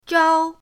zhao1.mp3